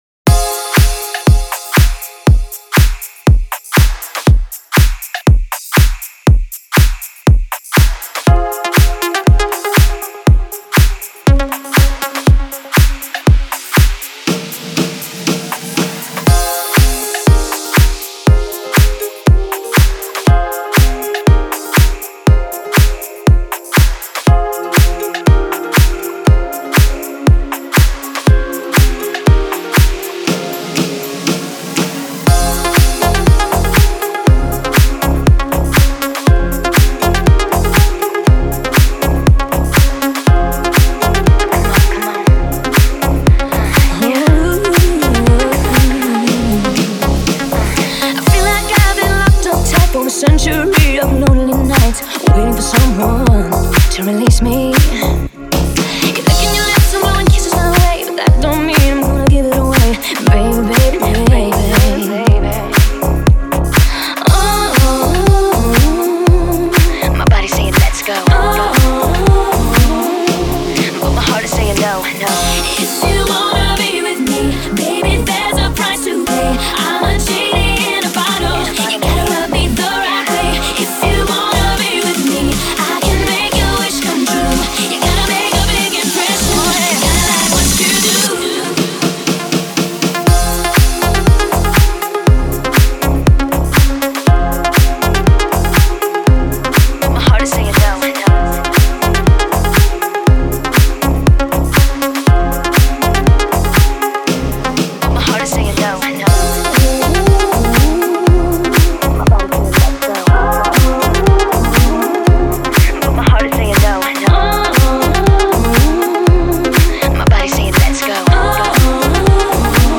Стиль: Dance / Pop